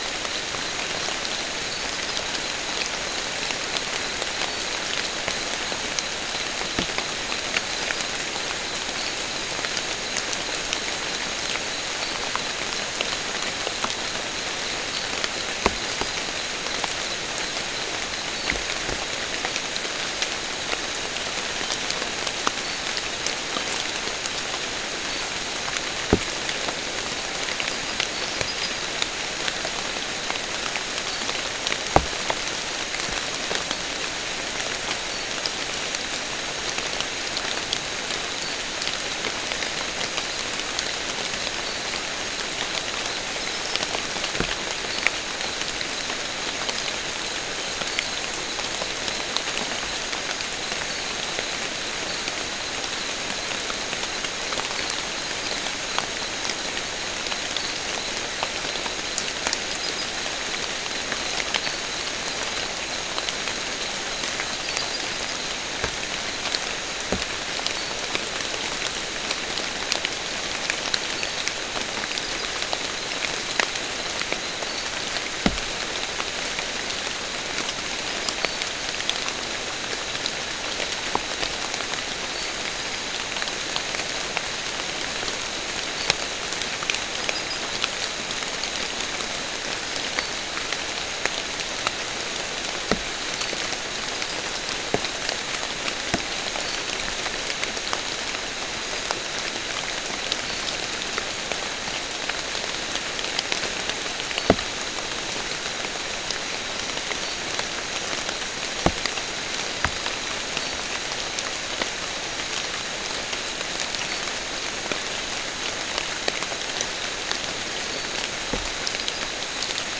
Soundscape
South America: Guyana: Turtle Mountain: 1
Recorder: SM3